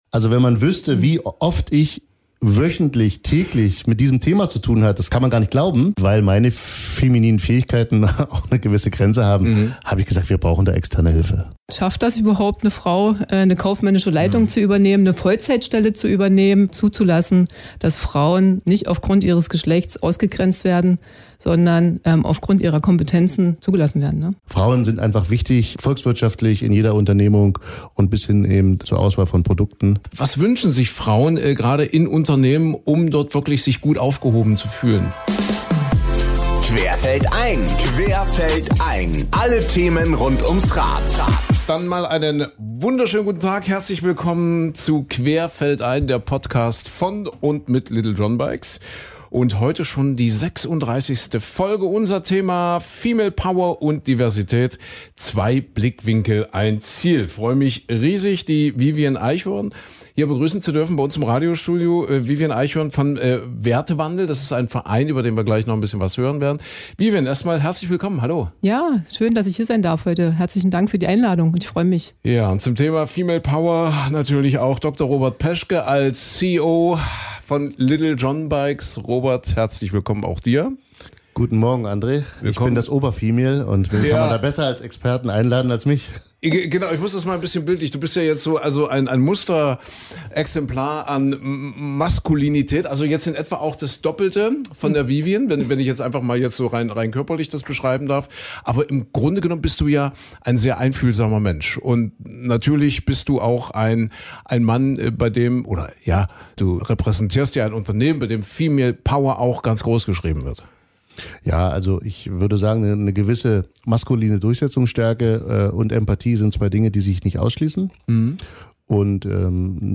Ein ehrliches, inspirierendes Gespräch über kulturellen Wandel, Widerstände – und wie echte Veränderung gelingt.